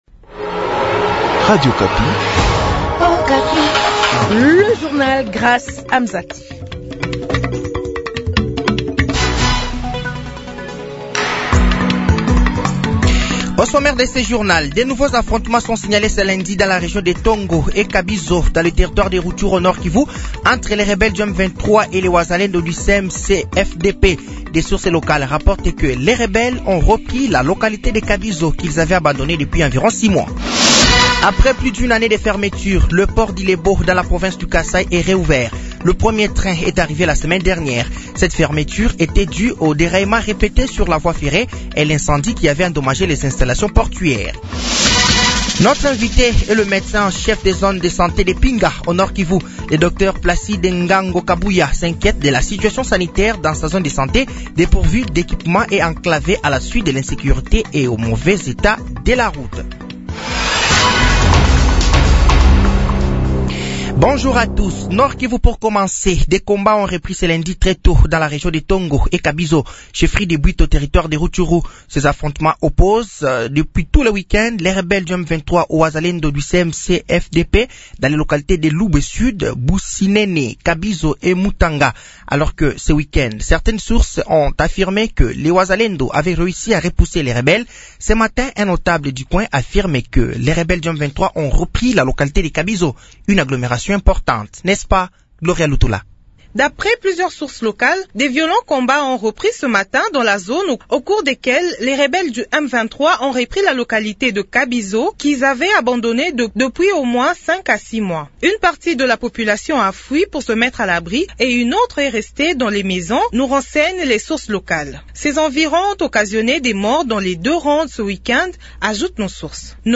Journal français de 15h de ce lundi 05 mai 2025